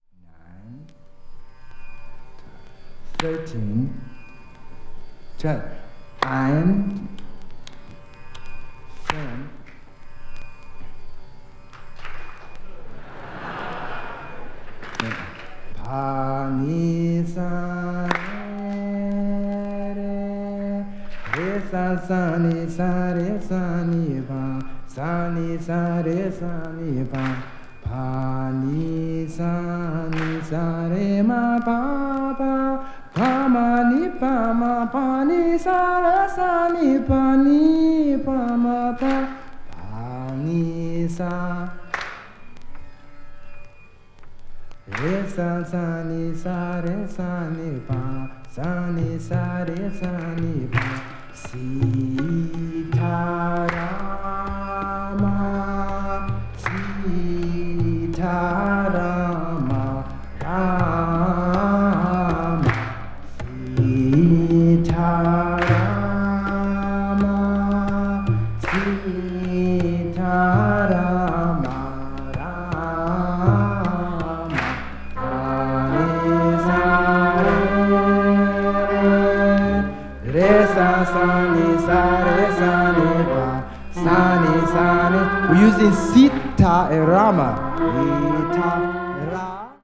(薄い擦れによるわずかなチリノイズが入る箇所あり)